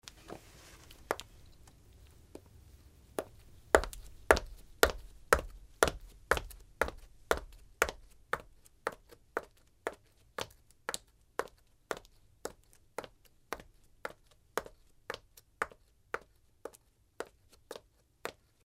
Pasos de una mujer con zapatos de tacón fino
Sonidos: Acciones humanas